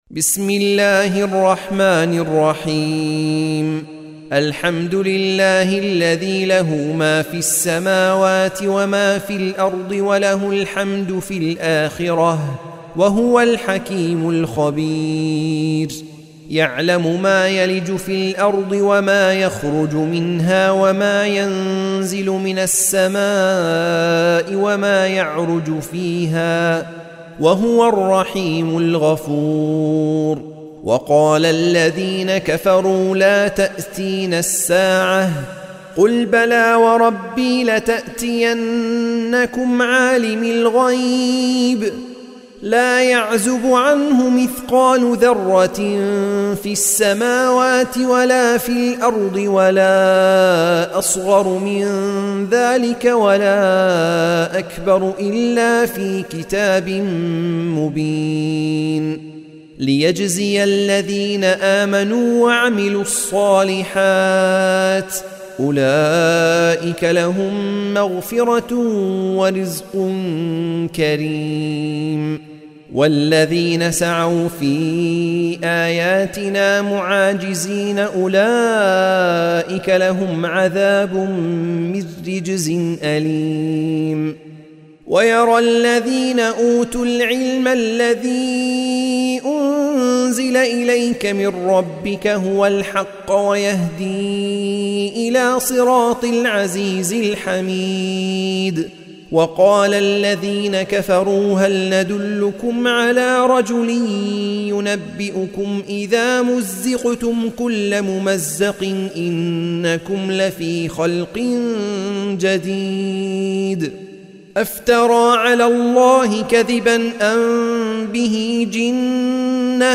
سورة سبأ مكية عدد الآيات:54 مكتوبة بخط عثماني كبير واضح من المصحف الشريف مع التفسير والتلاوة بصوت مشاهير القراء من موقع القرآن الكريم إسلام أون لاين